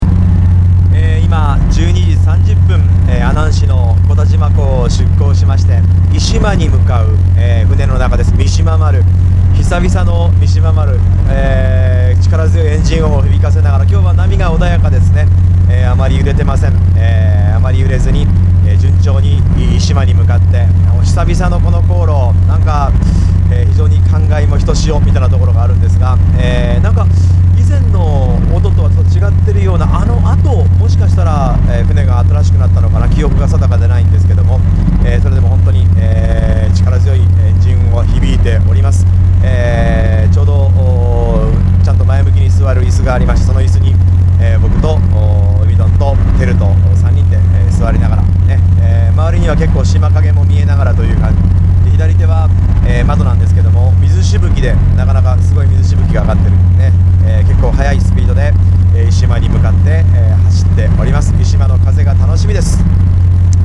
出航して速度を上げると、まるでプロペラ機かヘリコプターにでも乗ってるかのような轟音と振動で耳の奥がこそばゆい!!